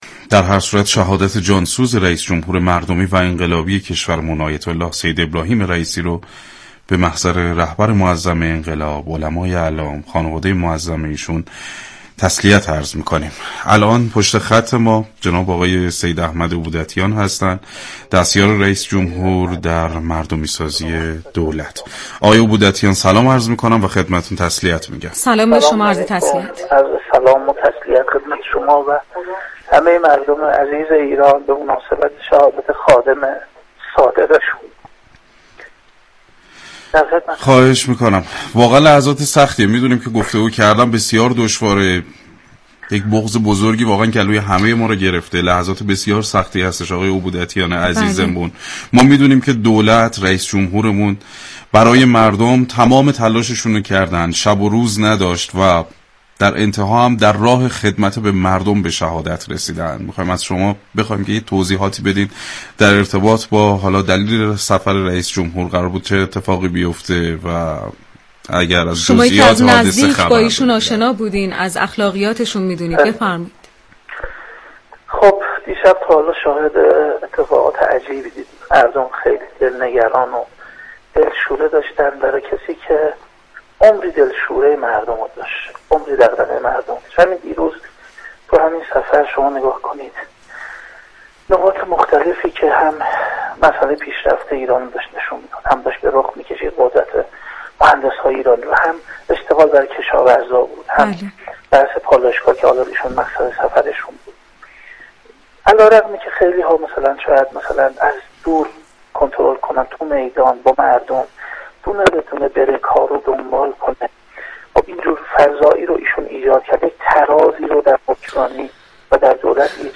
رادیو صبا در برنامه زنده «صبح صبا» در پی سانحه هوایی بالگرد رئیس جمهور و هیئت همراه به صورت ویژه همراه مخاطبان شد.